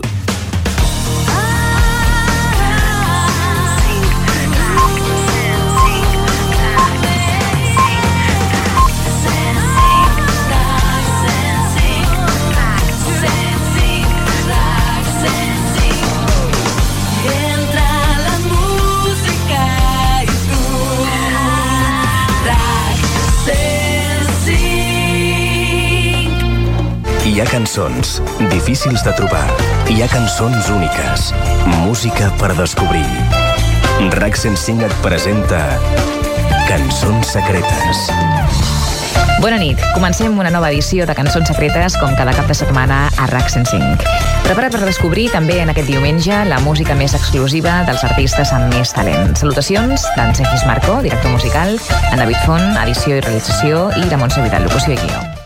Indicatiu de l' emissora. Careta del programa, salutació i presentació de l'equip
FM